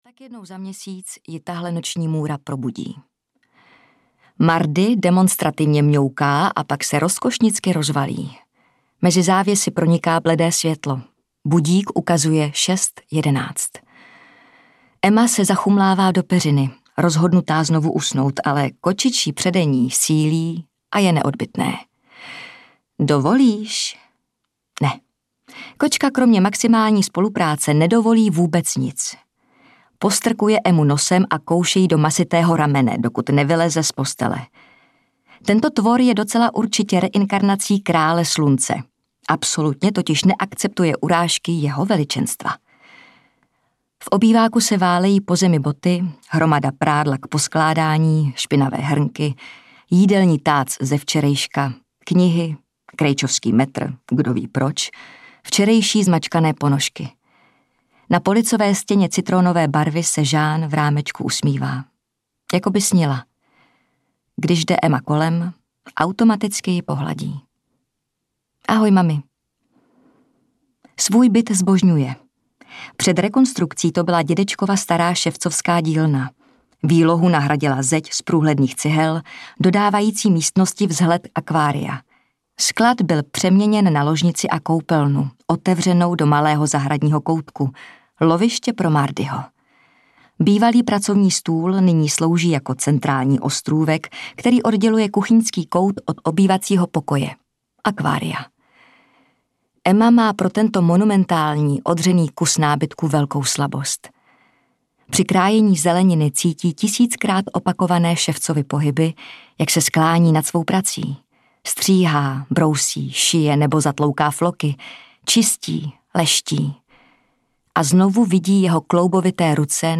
Emma audiokniha
Ukázka z knihy
• InterpretJana Plodková